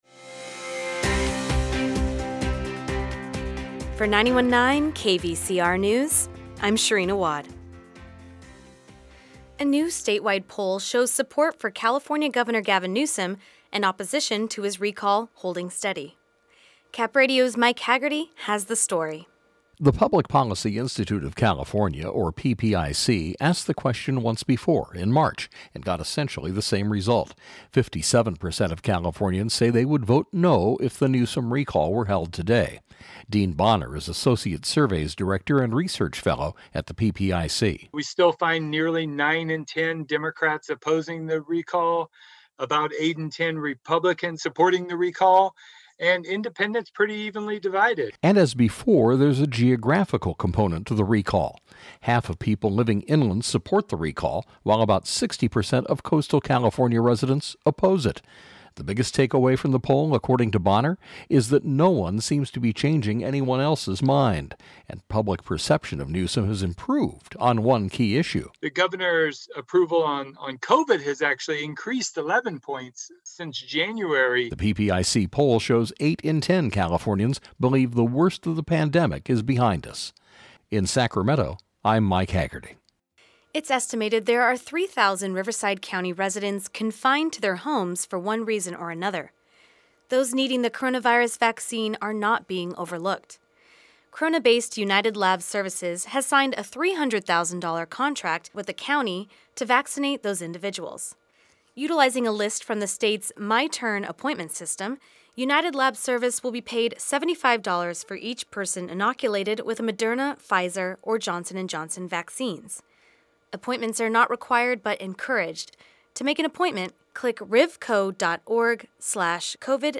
KVCR News has your daily news rundown at lunchtime.